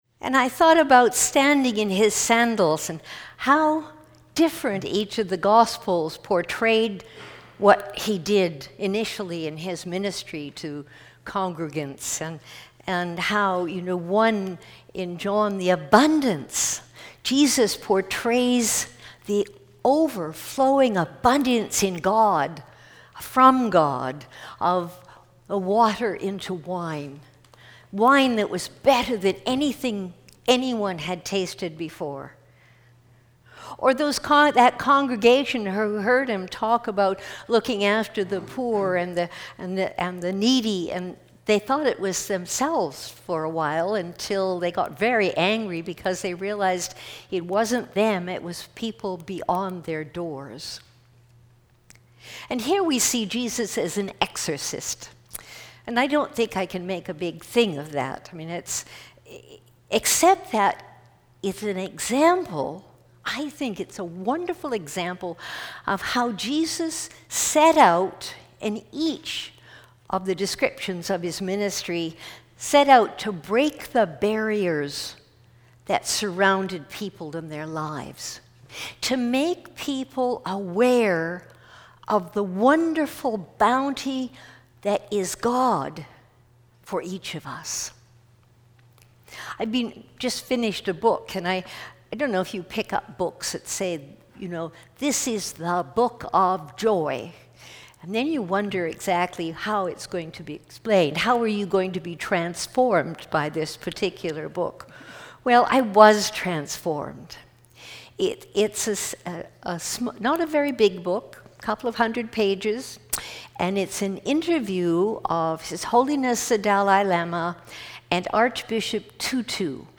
Fourth Sunday after the Epiphany 2018